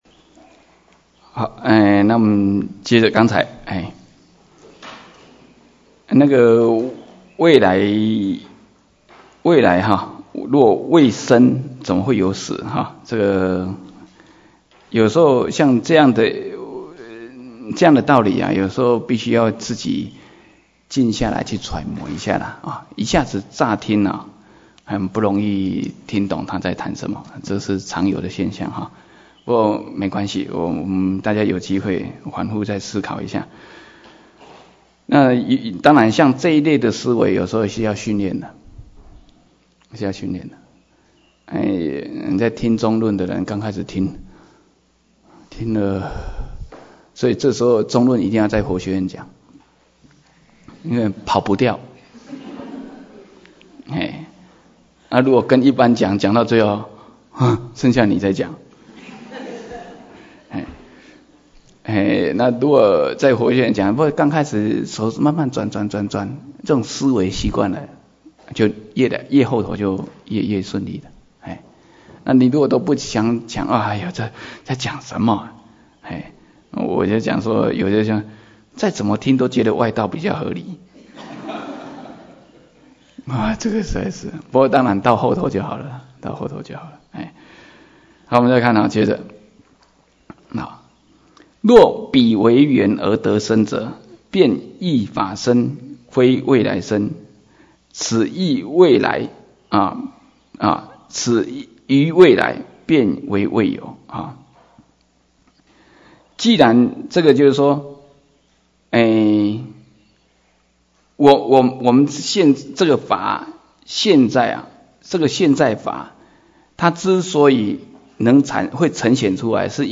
瑜伽师地论摄抉择分018(音軌有損.只45分).mp3